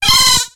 Cri de Wattouat dans Pokémon X et Y.